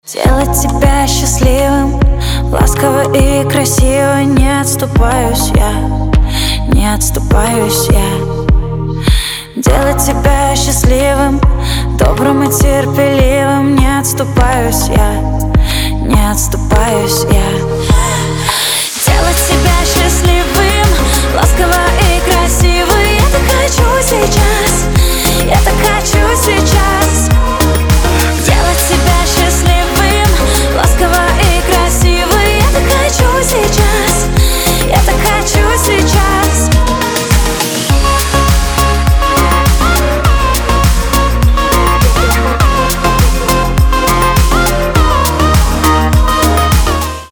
поп
романтичные